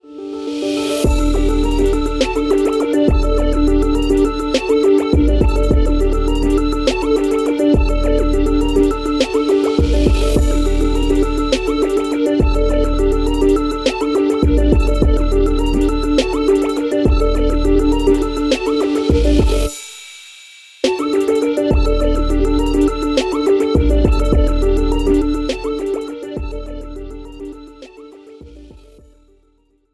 Without Backing Vocals. Professional Karaoke Backing Track.